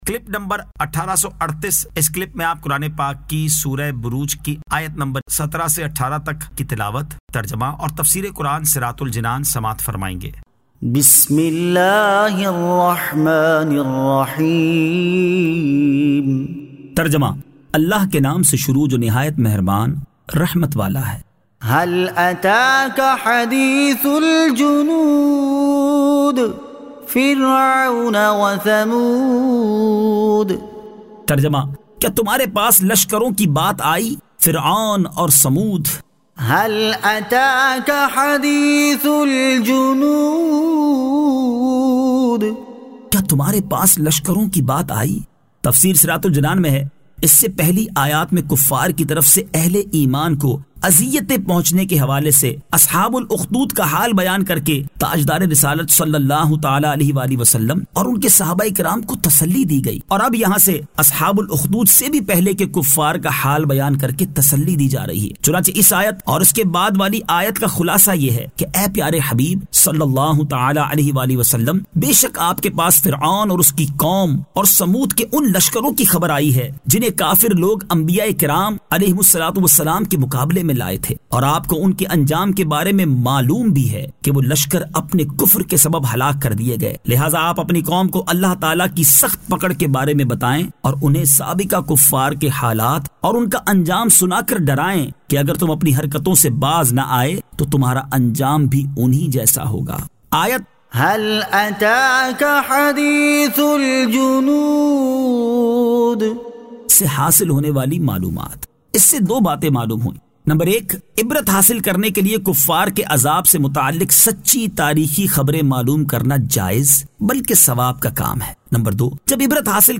Surah Al-Burooj 17 To 18 Tilawat , Tarjama , Tafseer